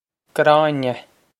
Gráinne Grah-in-yeh
Grah-in-yeh
This is an approximate phonetic pronunciation of the phrase.